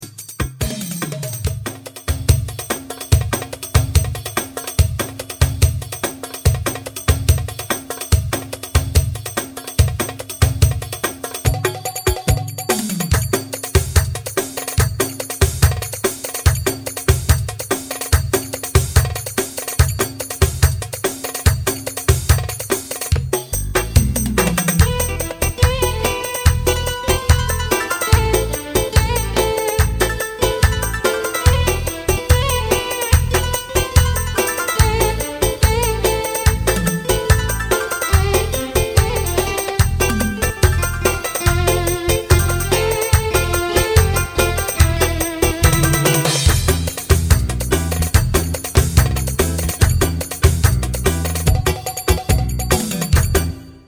Arabic